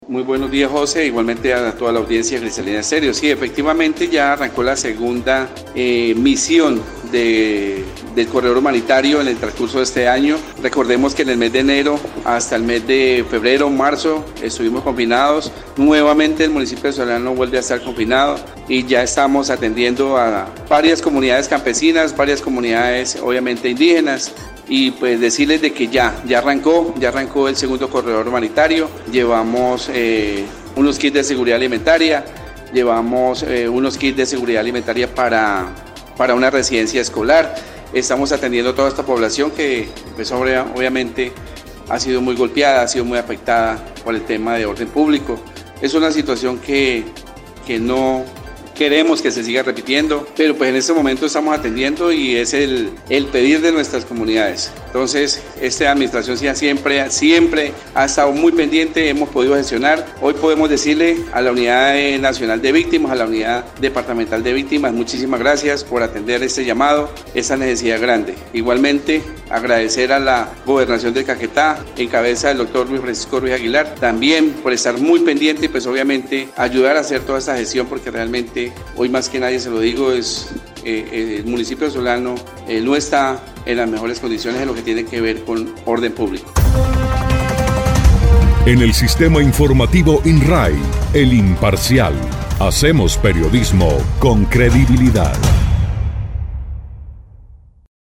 Así lo dio a conocer el alcalde del municipio de Solano, Luis Hernando Gonzales Barreto, quien dijo que esta segunda jornada se suma a una primera realizada durante el primer trimestre del año, cuando se presentó una seria alteración del orden público, que origino el confinamiento.
02_ALCALDE_LUIS_GONZALES_HUMANITARIA.mp3